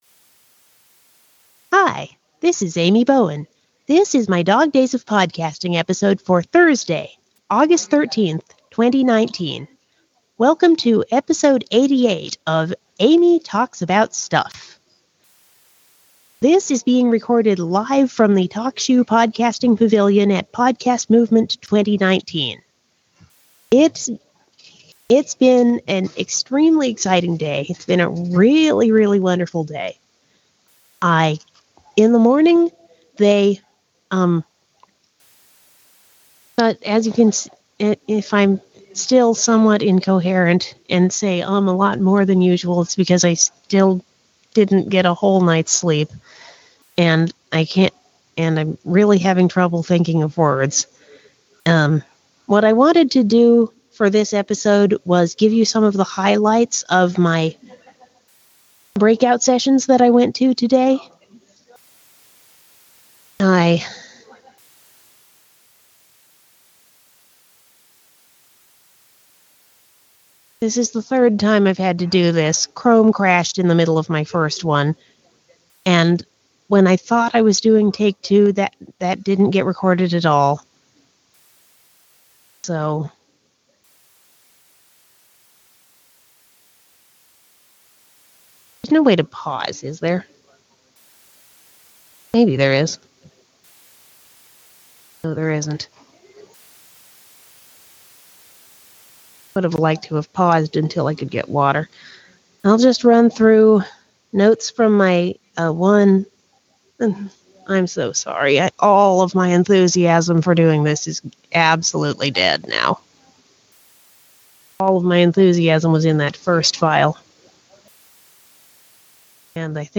I learned a lot and had a good time at Podcast Movement, despite tiredness and technical difficulties. This is being posted completely raw and unedited (because I can’t get Auphonic to delete the right parts). I have no idea why presets in Auphonic aren’t working right, either, so no theme music in this episode.